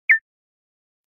im_notification_2.429cb6fa990cb31f7026.mp3